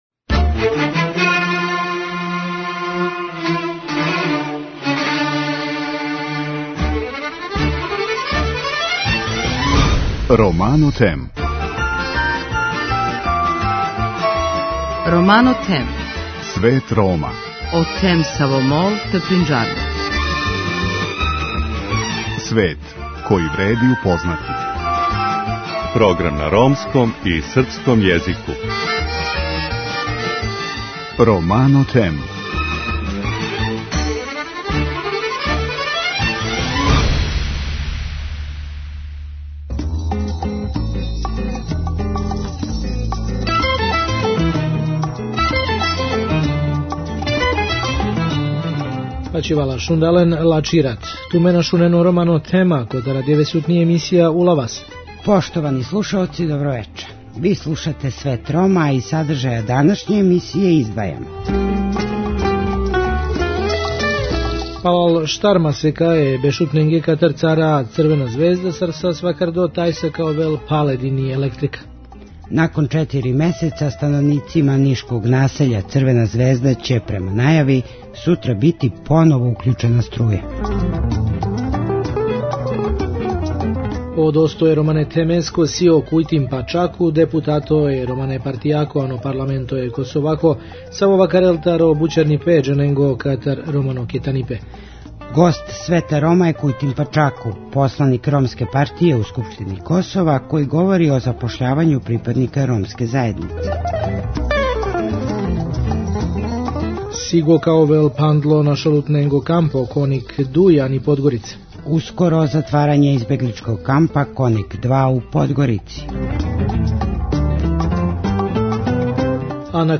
Гост Света Рома је Кујтим Пачаку, посланик Ромске партије у Скупштини Косова који говори о запошљавању припадника ромске заједнице у институцијама.